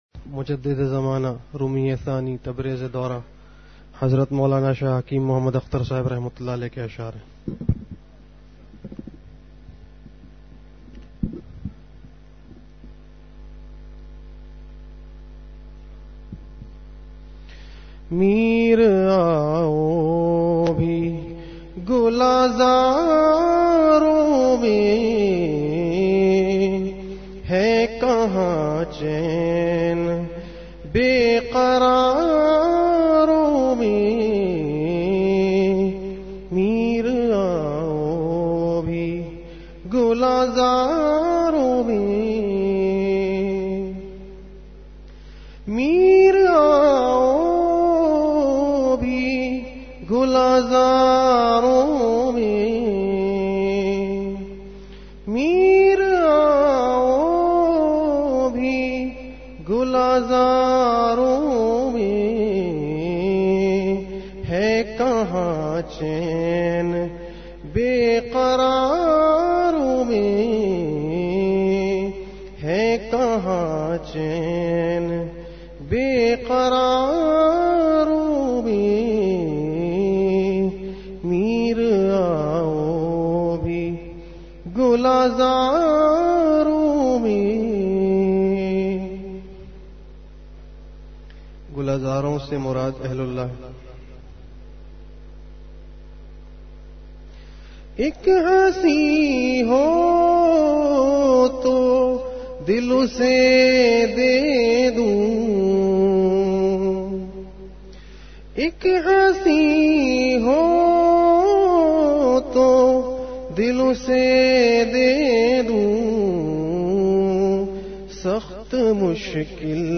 اشعار سنائے
درسِ قرآن
اصلاحی مجلس کی جھلکیاں